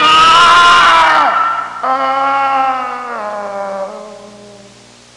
Groan Sound Effect
Download a high-quality groan sound effect.
groan-2.mp3